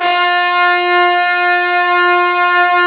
Index of /90_sSampleCDs/Roland LCDP06 Brass Sections/BRS_Fat Section/BRS_Fat Pop Sect